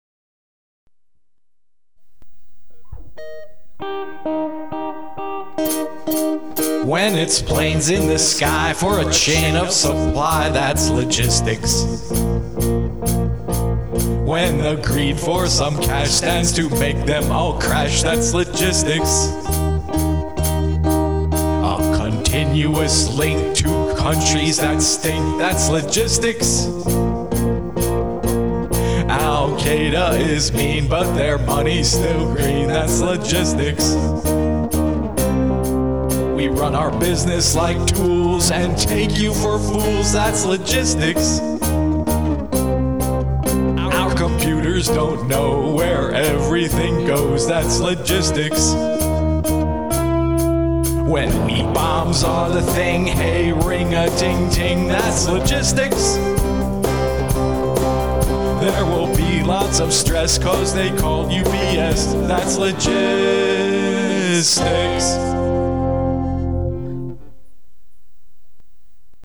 Posted in Rock 'n' Roll
Played all the instruments, too.